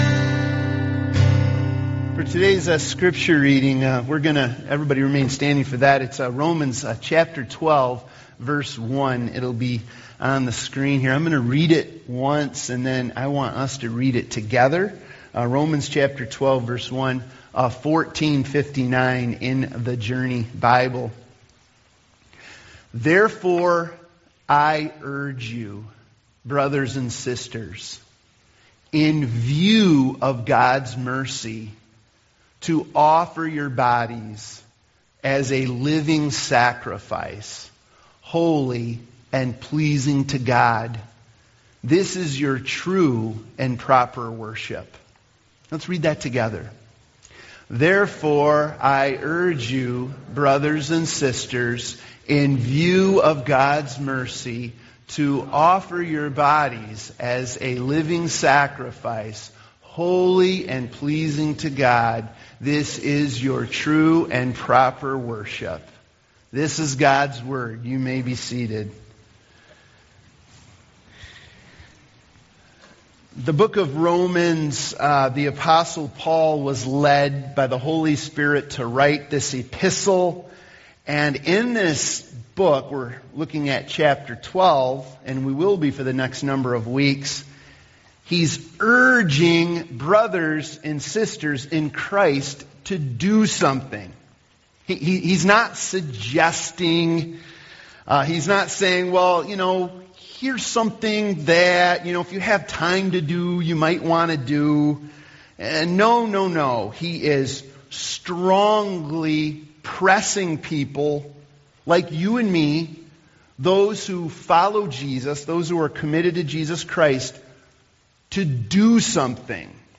Everyday Worship Service Type: Sunday Morning « Virtual Freedom or Real?